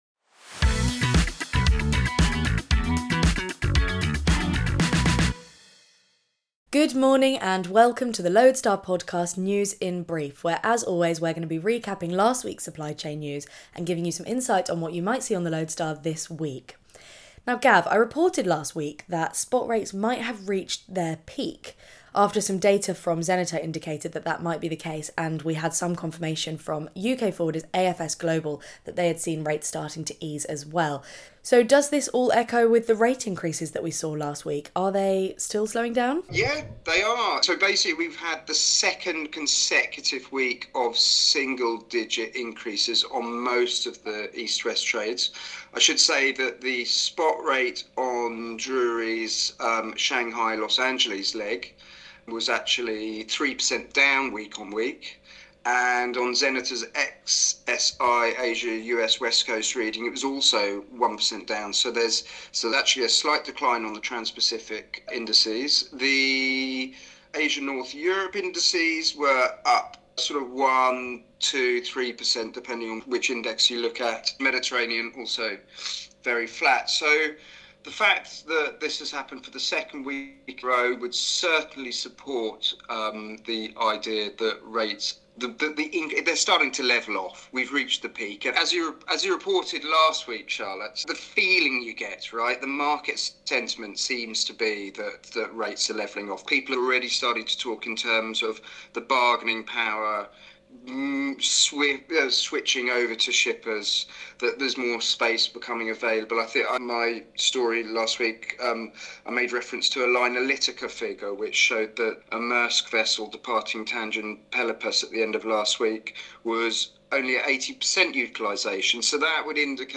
So, what are you waiting for? This bite-sized news podcast will catch you up on anything you might ha